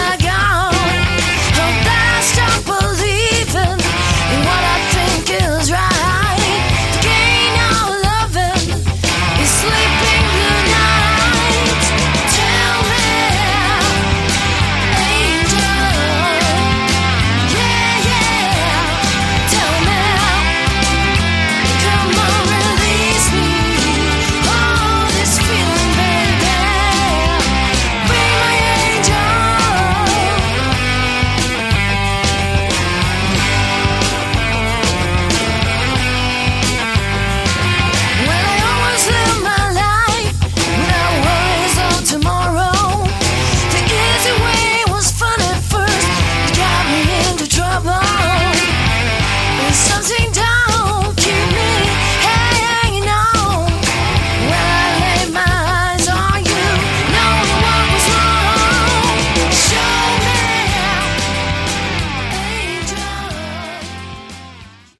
Category: AOR
vocals
guitars
drums